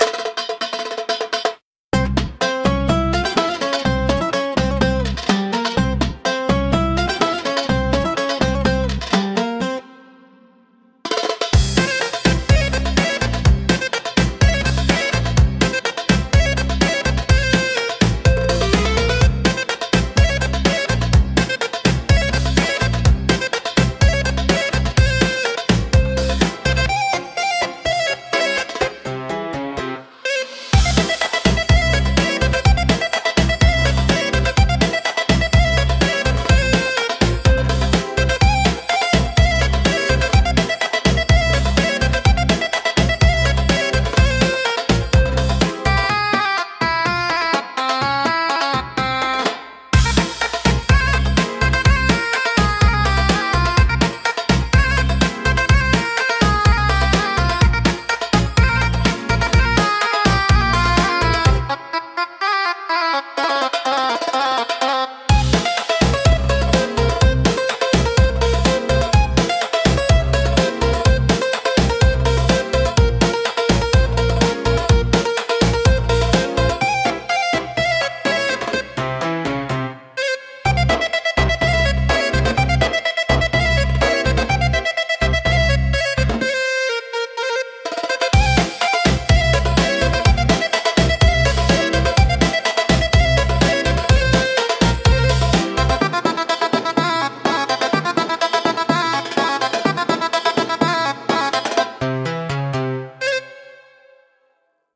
アラビア音楽は、中東地域に伝わる伝統的な音楽スタイルで、独特の旋律（マカーム）と複雑なリズムが特徴です。
ウードやカヌーン、ダラブッカなどの民族楽器が使われ、神秘的でエキゾチックな雰囲気を醸し出します。
独特のメロディとリズムで聴く人の感覚を刺激します。